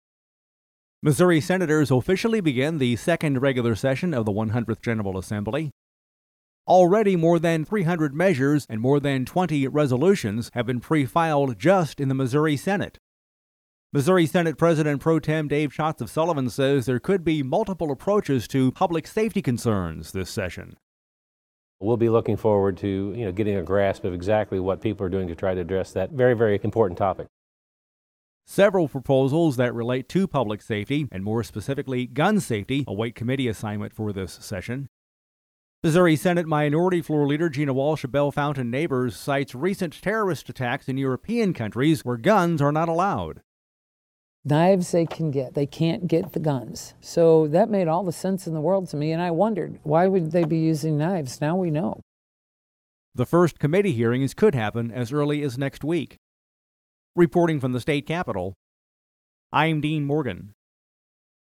Jan. 8: Missouri senators kick off the 2020 legislative session at the Capitol. Next up for lawmakers will be the first committee hearings of the year. We’ve included actualities from Missouri Senate President Pro Tem Dave Schatz, R-Sullivan, and Missouri Senate Minority Floor Leader Gina Walsh, D-Bellefontaine Neighbors